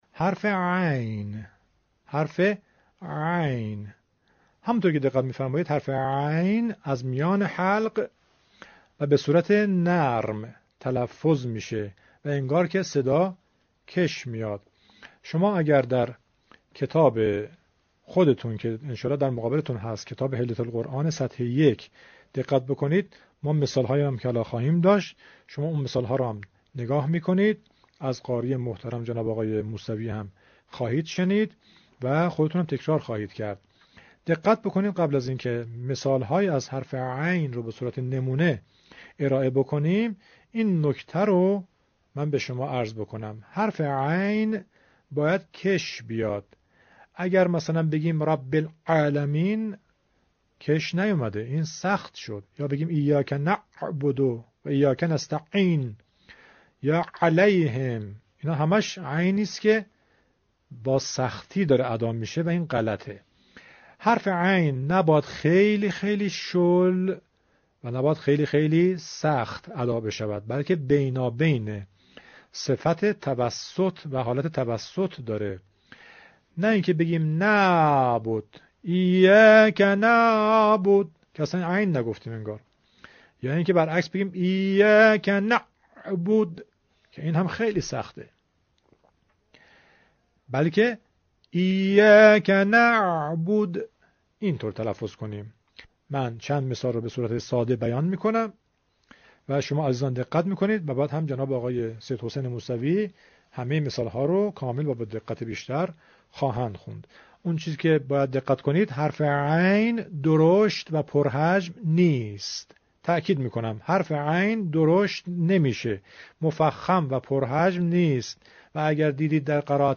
این حرف از حروف حلقی است و مانند حرف «ح» در وسط حلق ساخته می شود.
۴- فاصله بین سقف و سطح دهان را کم کنید تا حرف «ع» نازک تلفظ شود.